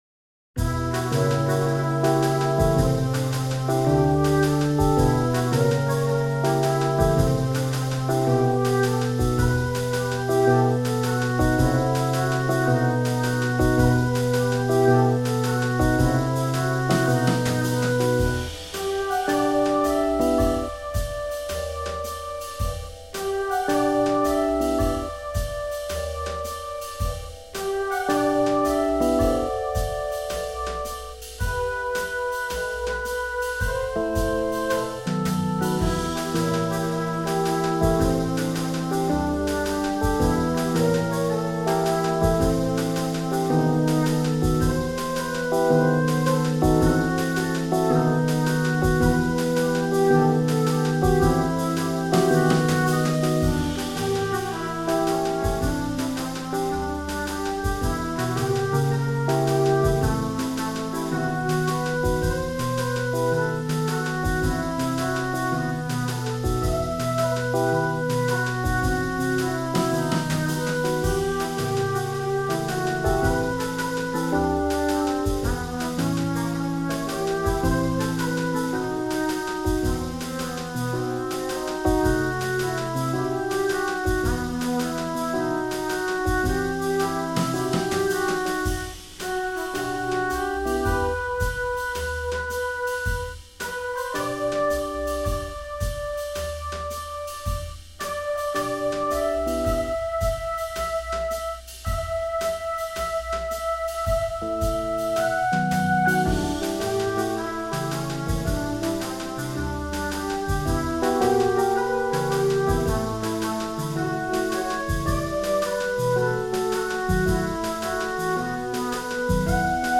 un album musical composé par l’intelligence artificielle